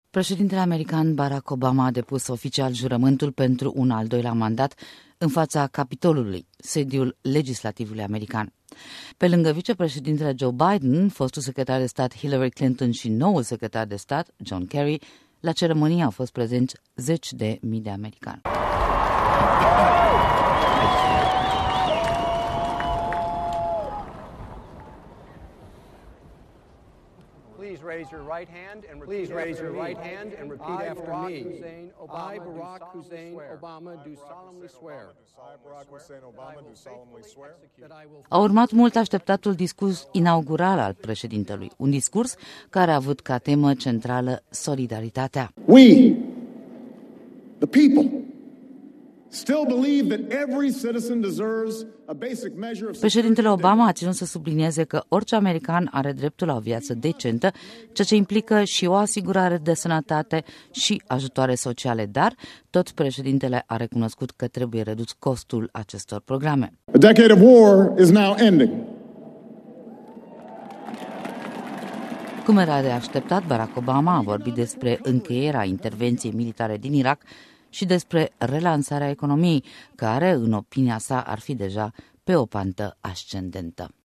Discursul inaugural al preşedintelui Barack Obama